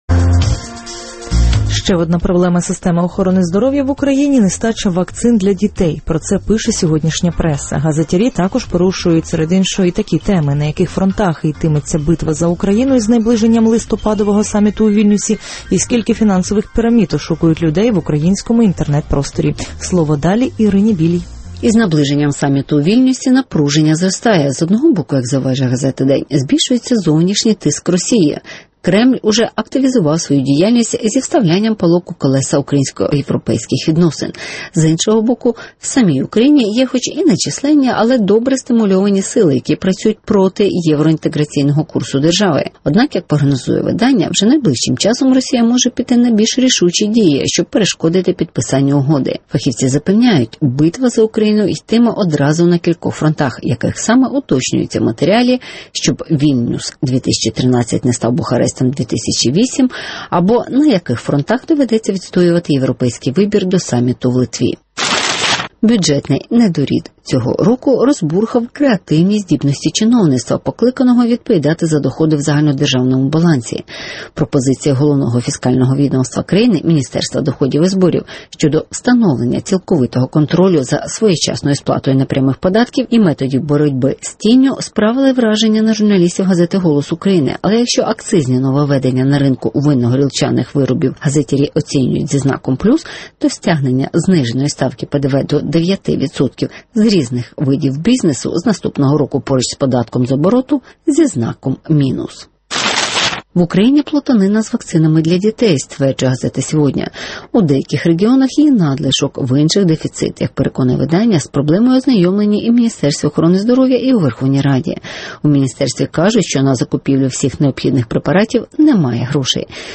Битва за Україну до саміту в Литві (огляд преси)